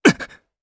Evan-Vox_Damage_jp_01.wav